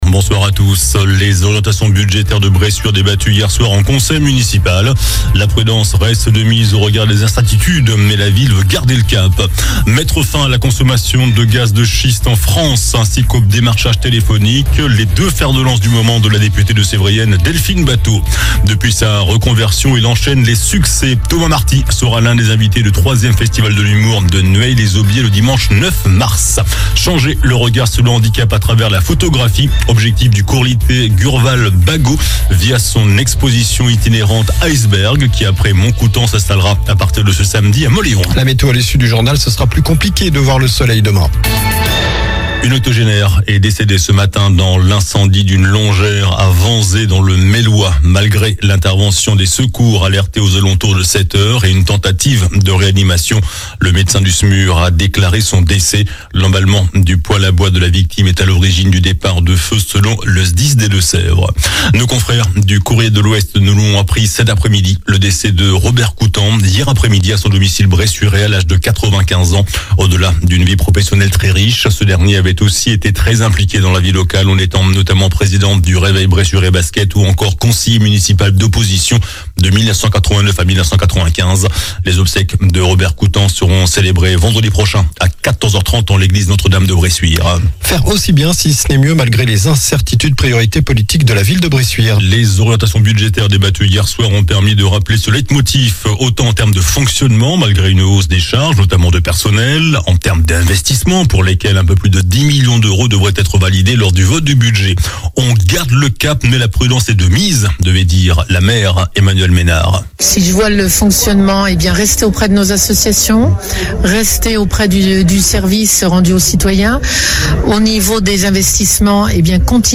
JOURNAL DU MARDI 18 FEVRIER ( SOIR )